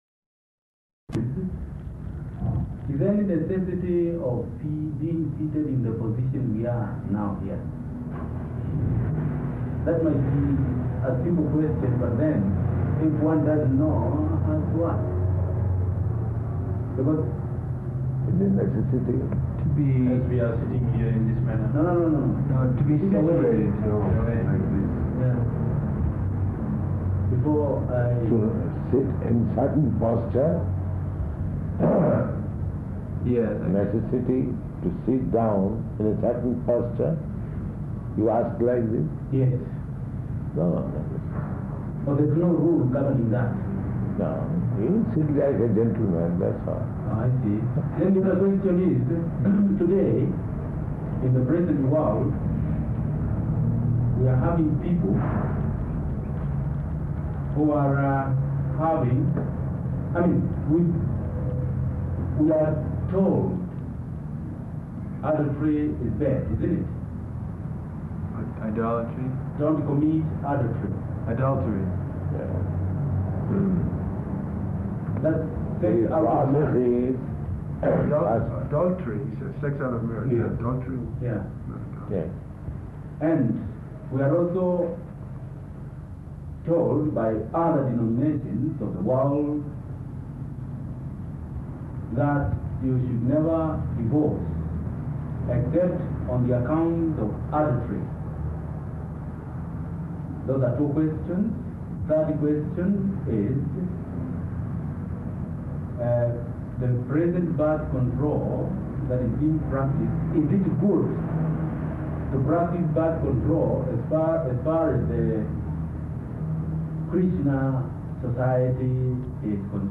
Room Conversation with African Intelligentsia
Type: Conversation
Location: Nairobi